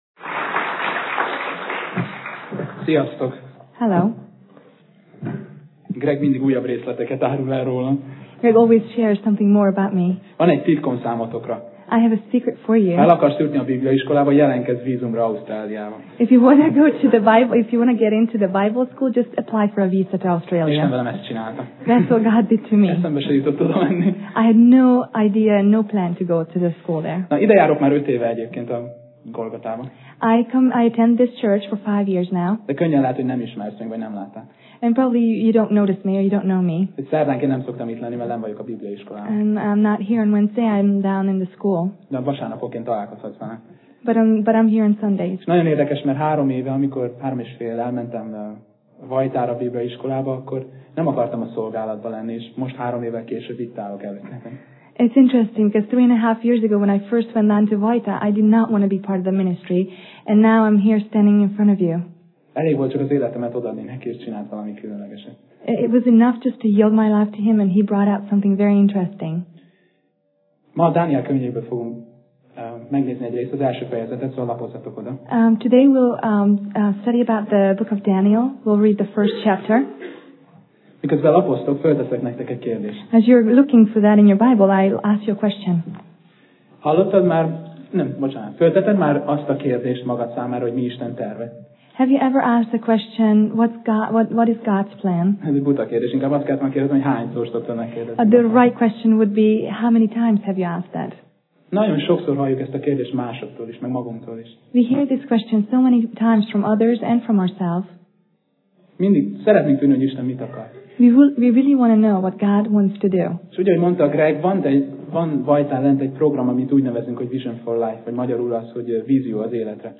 Tematikus tanítás Passage: Dániel (Daniel) 1:1-21 Alkalom: Szerda Este